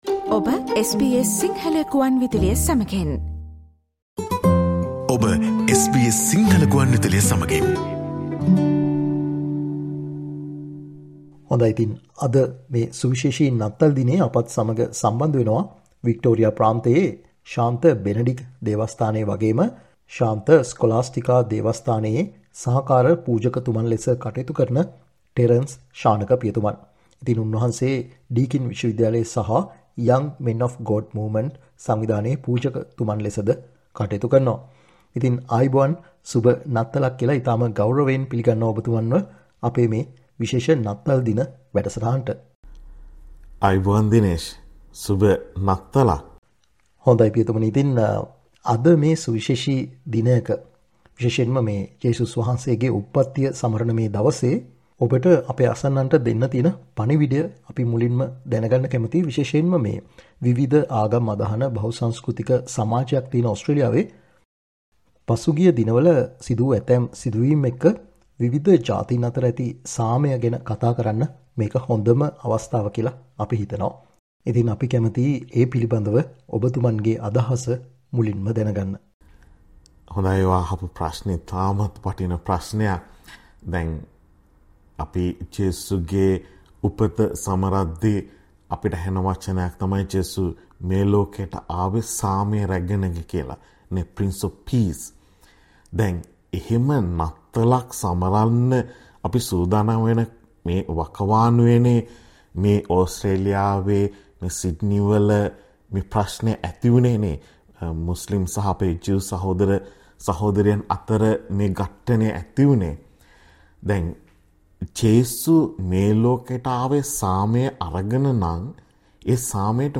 SBS සිංහල සේවය සමග කල සාකච්චාවට සවන් දෙන්න